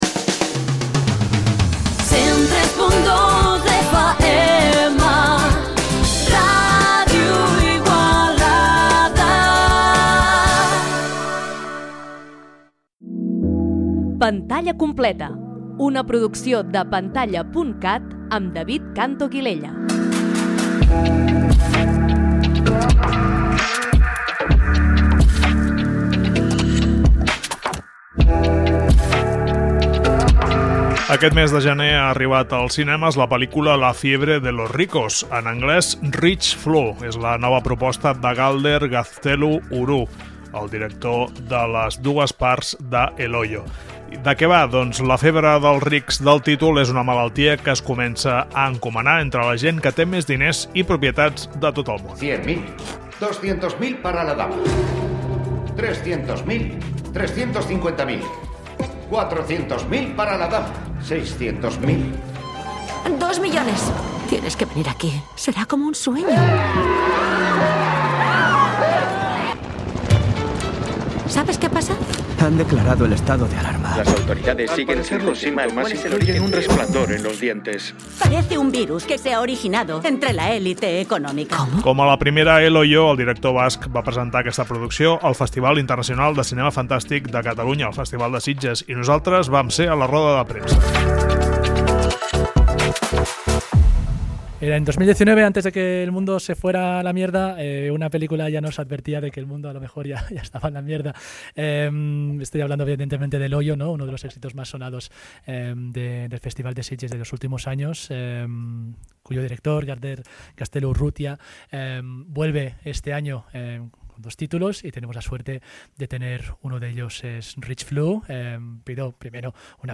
També sentirem l’entrevista al Festival de Sitges als actors Megan Montaner i Miguel Ángel Silvestre, protagonistes de ’30 monedas’, sèrie d’HBO Max.